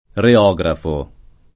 reografo [ re 0g rafo ] s. m.